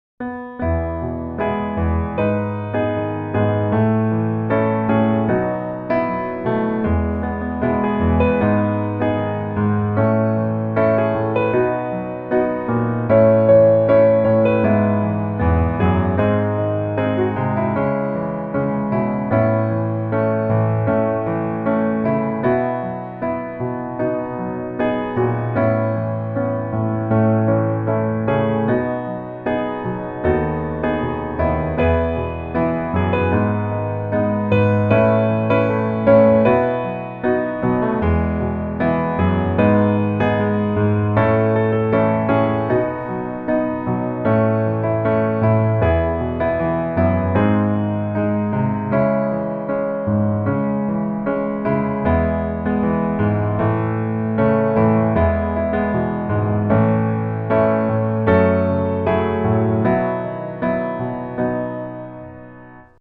Sung during the Northern California College Conference in October 2018.
E Major